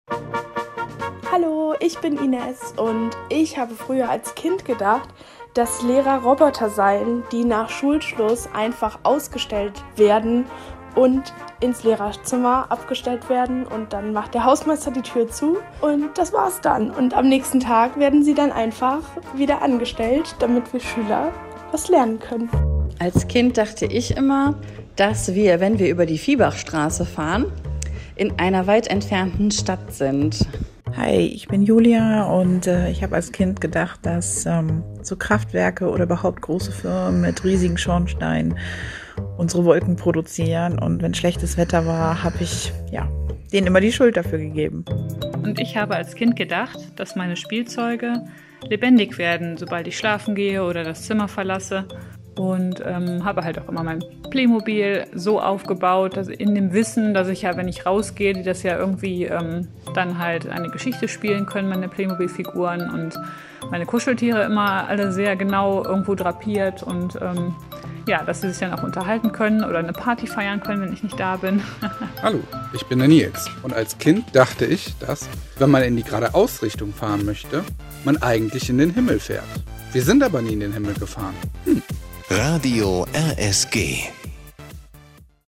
collage_als_kind_dachte_ich_ul___drop.mp3